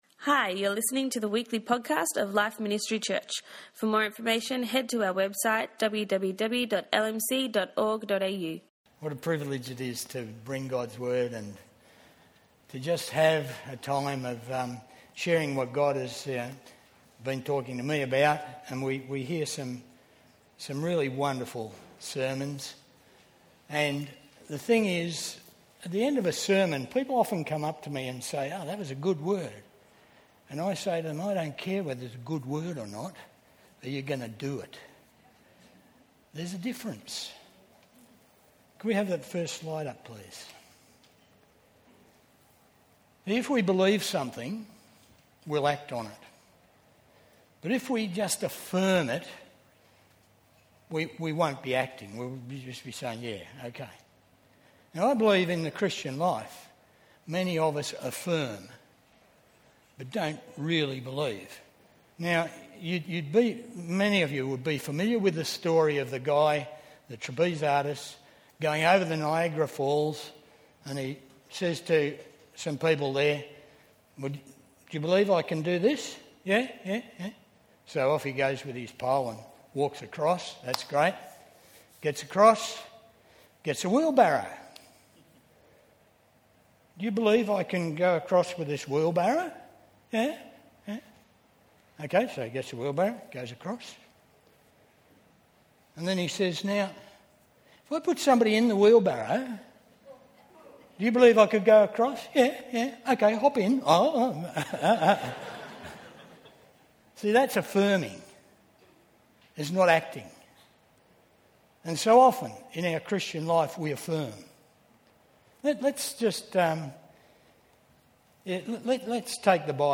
always preaches a challenge message, requiring a response from us. In this message, he speaks on the difference between believing something, and only affirming it - with regard to the Word of God!